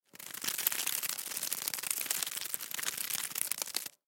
На этой странице собраны редкие записи, демонстрирующие разнообразие акустических сигналов этих многоножек: от шуршания ножками до стрекотания в моменты опасности.
звук перебирающих ног сколопендры